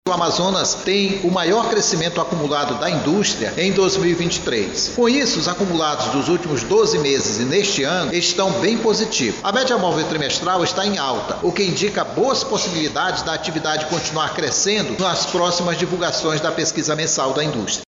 SONORA-2-AUMENTO-PRODUCAO-INDUSTRIAL-2.mp3